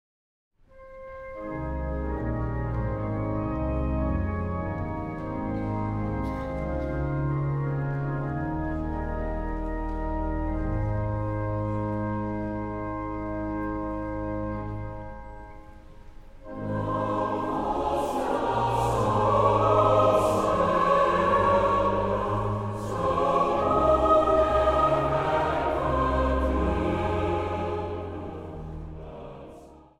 Zang | Gemengd koor
Zang | Jongerenkoor